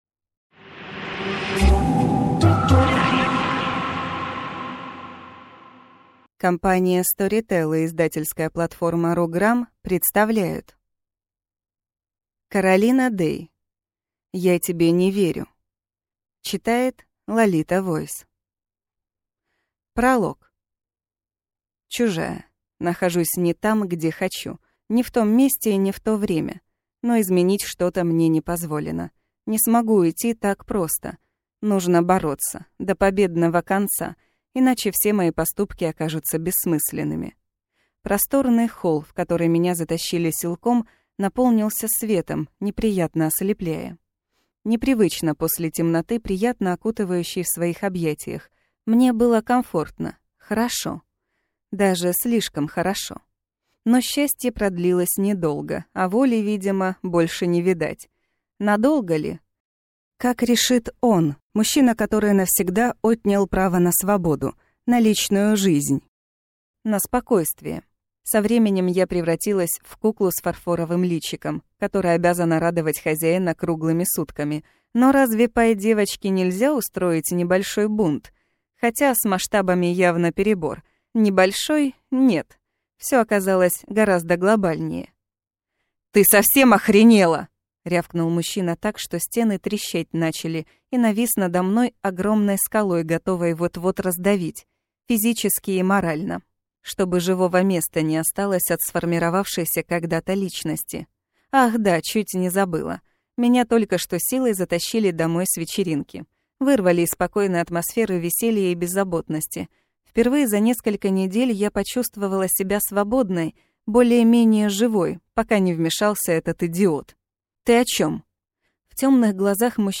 Аудиокнига Я тебе (не) верю | Библиотека аудиокниг
Прослушать и бесплатно скачать фрагмент аудиокниги